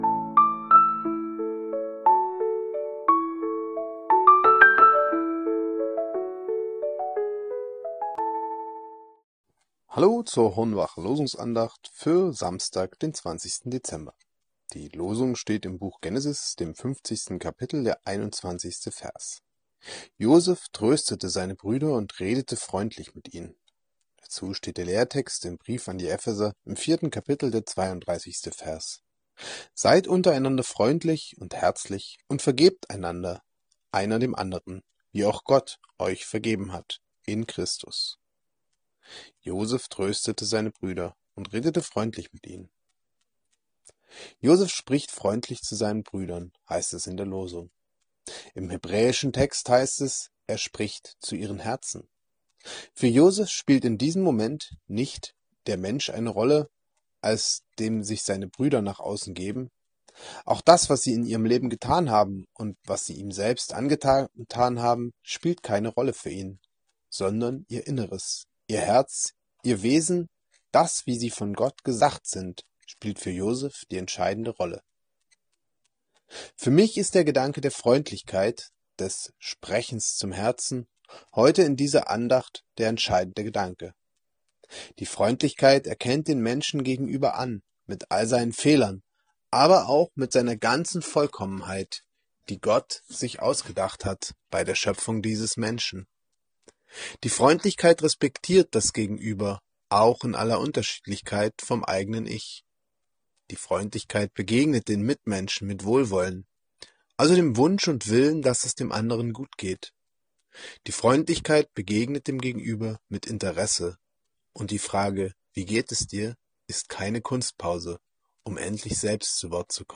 Losungsandacht für Samstag, 20.12.2025